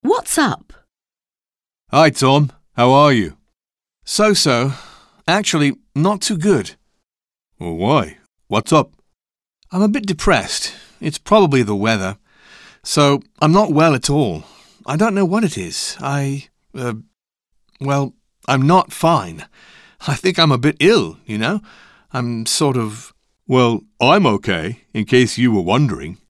Dialogue - What's up?